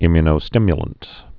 (ĭmyə-nō-stĭmyə-lənt, ĭ-my-)